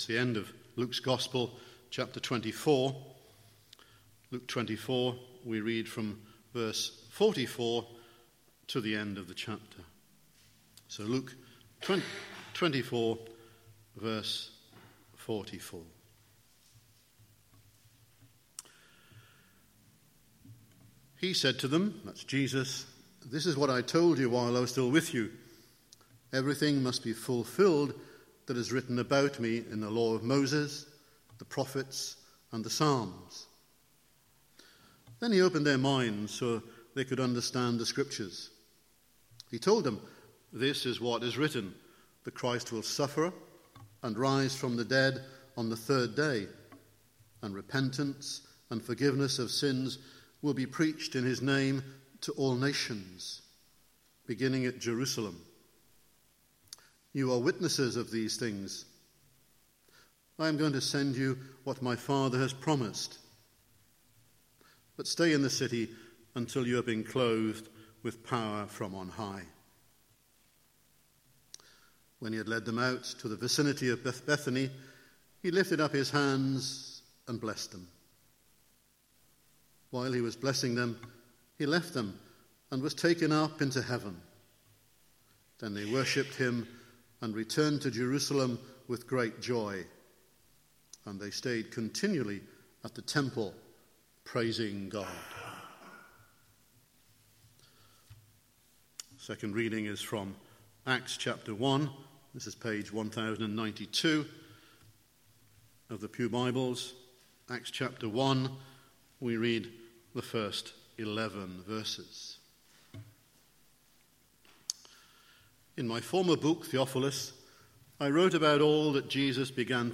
Guest Speaker , Evening Service